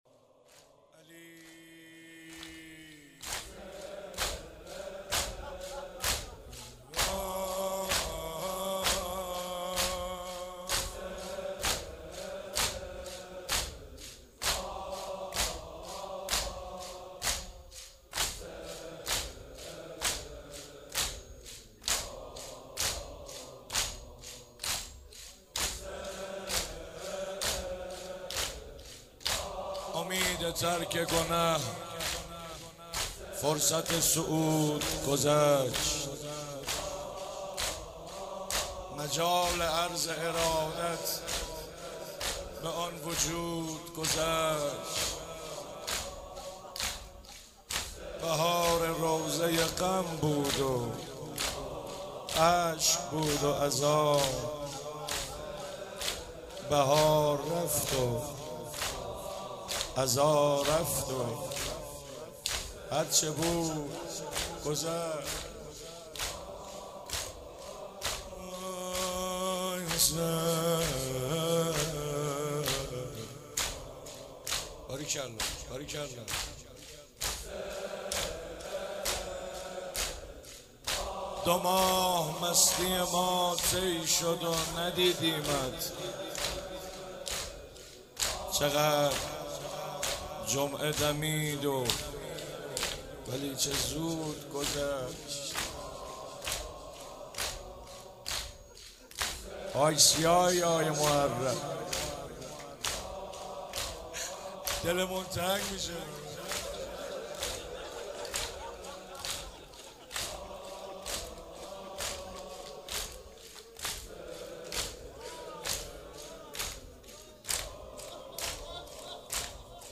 چهاراه شیرودی حسینیه حضرت زینب(س)
روضه